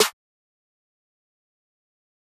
Snare (All Mine).wav